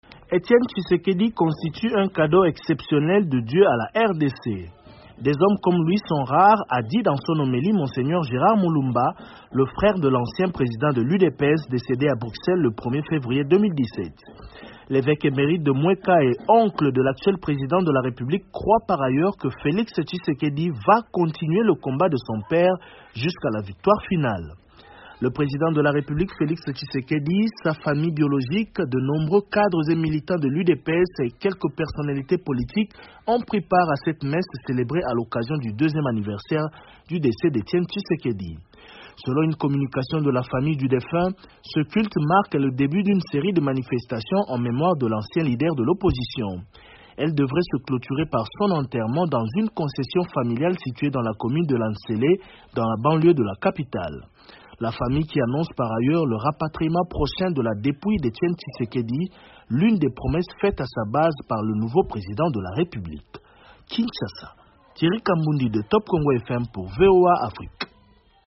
En RDC, deux ans jour pour jour après sa mort à Bruxelles, la dépouille d’Etienne Tshisekedi sera bientot transférée a Kinshasa. Son fils, Felix Tshisekedi, devenu président, a assisté ce vendredi à une messe d’action de grâce célébrée. Le reportage